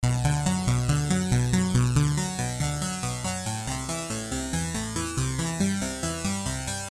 技术合成器 Arp
描述：短暂的技术风格模式，140bpm
Tag: 140 bpm Techno Loops Synth Loops 1.15 MB wav Key : Unknown